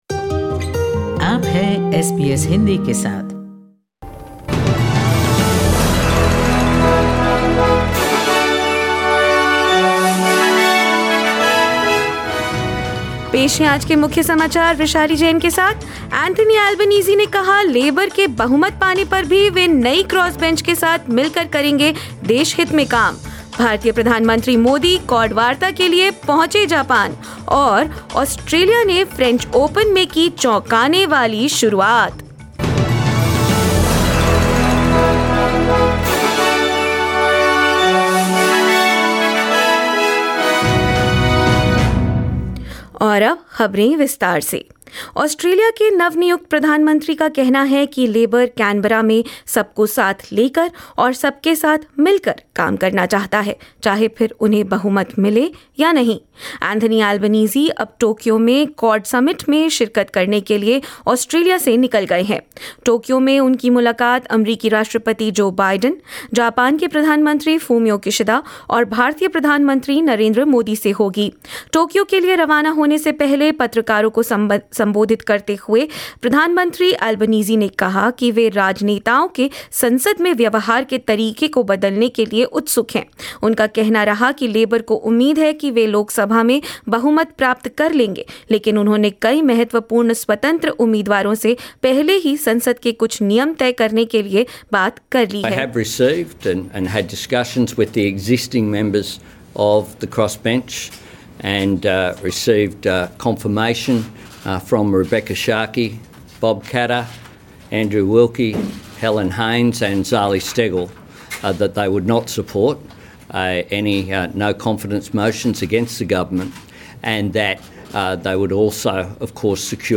In this latest SBS Hindi bulletin: Anthony Albanese takes off for Tokyo to attend his first international conference as the Prime Minister of Australia; Indian Prime Minister Narendra Modi reaches Tokyo to attend Quad Summit; Australian tennis player Jason Kubler gives 11-strong Down Under squad hope in French Open and more news.